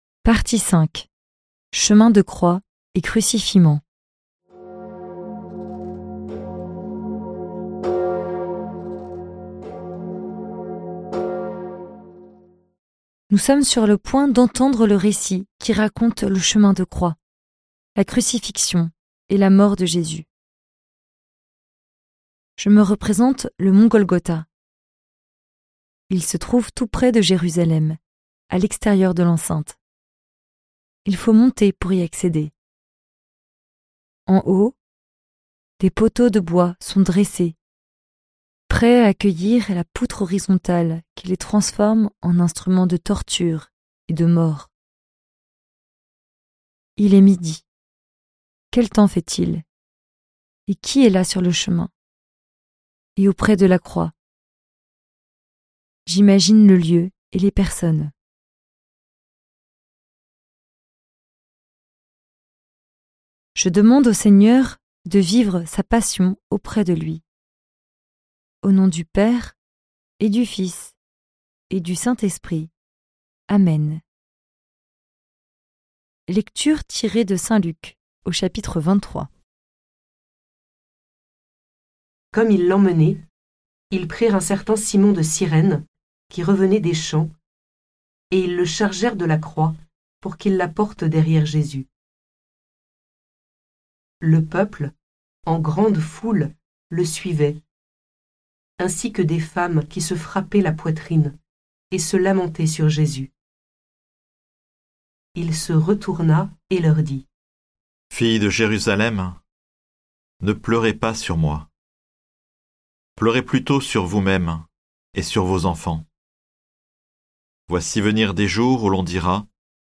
Méditation guidée suivant un livre biblique, une encyclique, des psaumes, ou un thème de la vie chrétienne.
Musiques